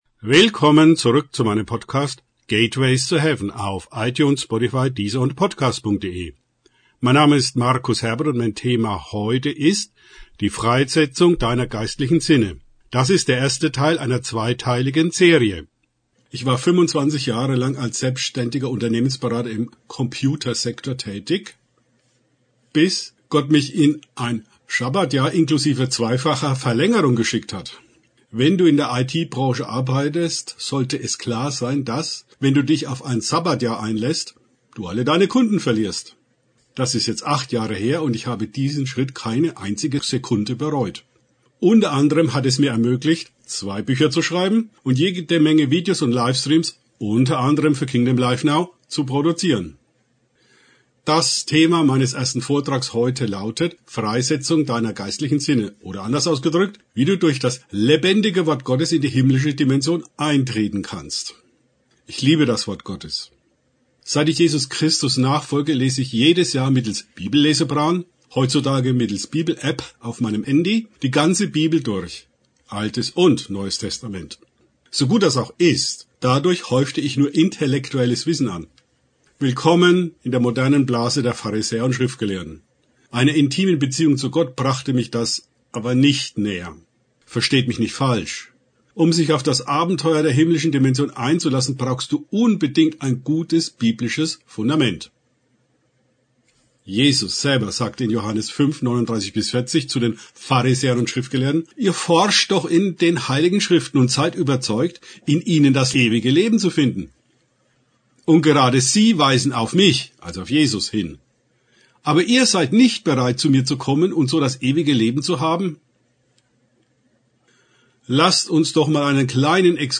Hast du ebenfalls Sehnsucht, Gott nicht nur aus zweiter oder dritter Hand zu erleben? In meinem ersten Vortrag werde ich die biblischen Grundlagen dazu legen, wie du durch das lebendige Wort Gottes in die himmlische Dimension im Geist eintreten kannst. Dazwischen gibt es praktische Übungen, um das gehörte zu vertiefen.